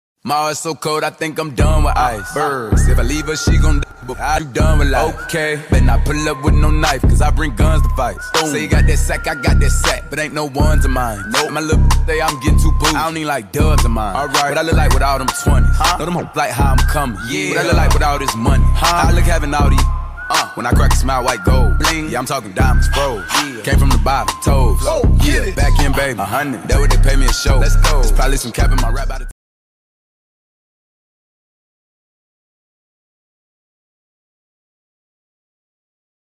Con música trap Mp3 Sound Effect 🚨🇺🇸 | Con música trap de fondo, el Servicio de Control de Inmigración (ICE) presentó los nuevos vehículos con el nombre y logo de la agencia, que utilizará para los operativos contra inmigrantes ilegales.